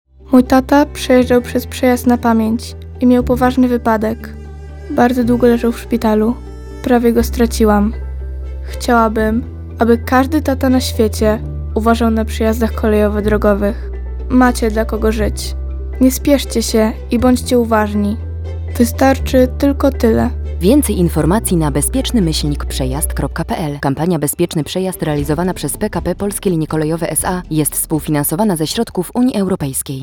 wypowiedź dziewczynki 2023 Spoty audio kampanii społecznej "Bezpieczny Przejazd"
spot radiowy